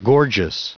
Prononciation du mot gorgeous en anglais (fichier audio)
Prononciation du mot : gorgeous
gorgeous.wav